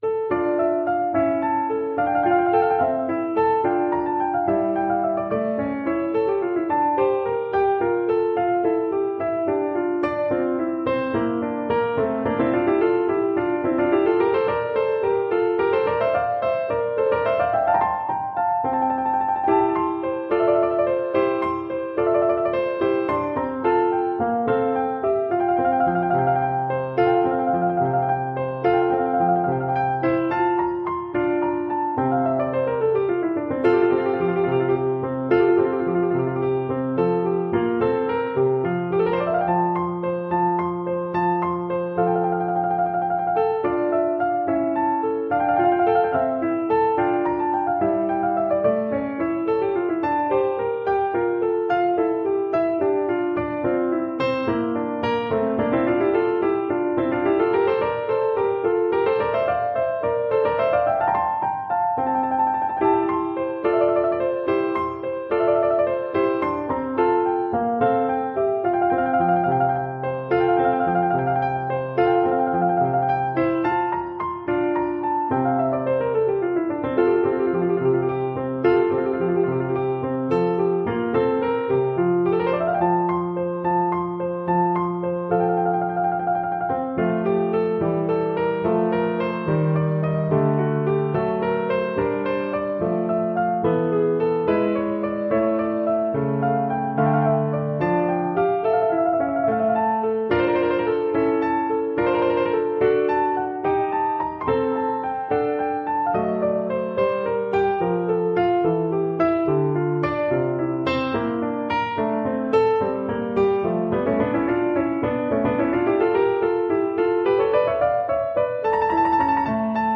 This piece is in D minor, but it is light and beautiful.
• Duet (Violin / Viola)